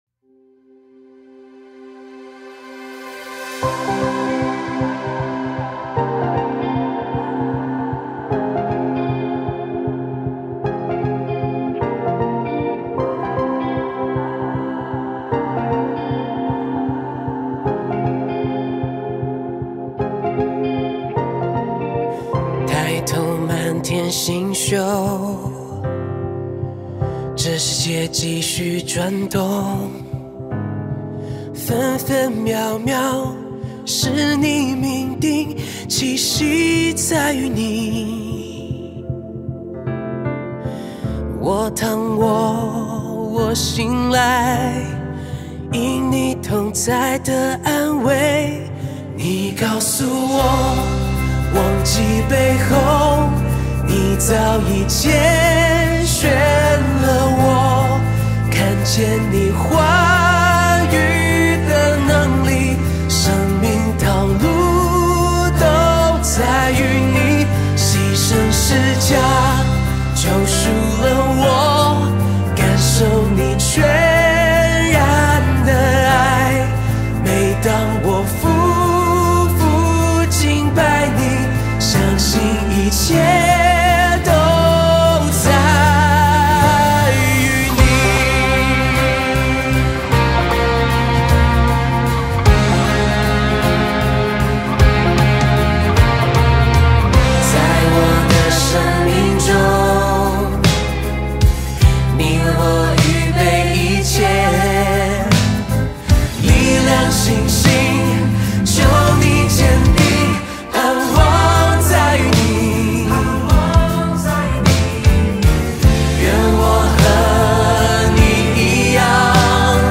合唱团 Choir
电吉他 Electric Guitar
贝斯 Bass
鼓 Drum